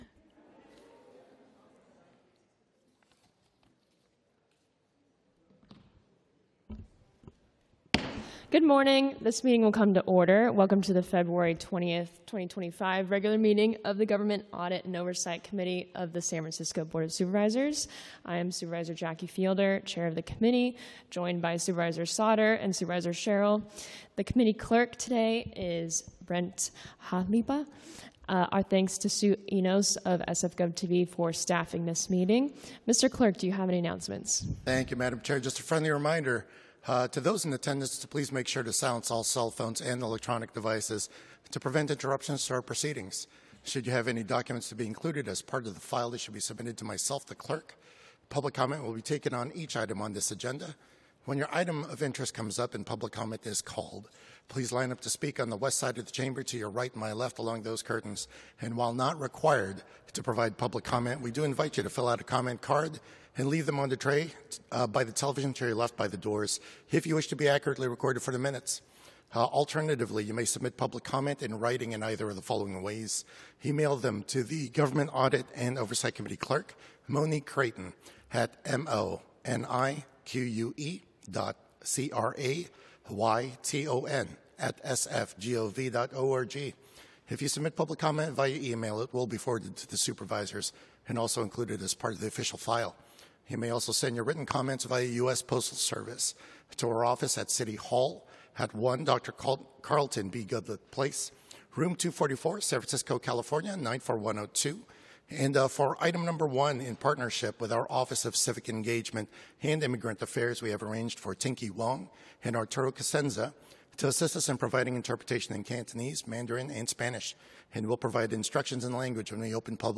BOS-Government Audit and Oversight Committee - Regular Meeting - Feb 20, 2025